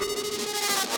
Belong To The World Riser FX.wav